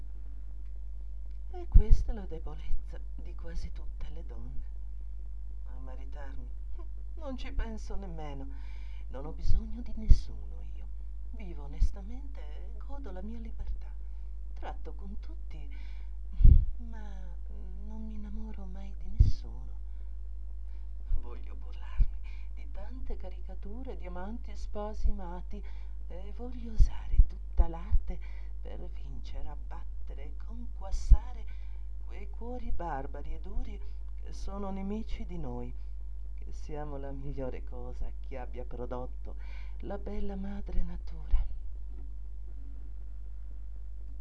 MONOLOGHI
Monologo 3